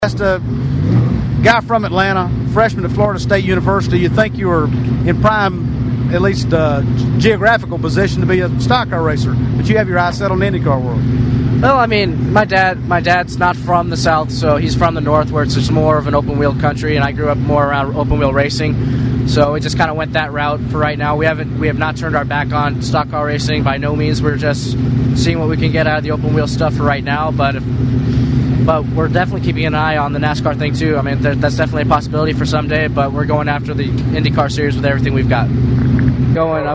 AUTO RACING ACTUALITY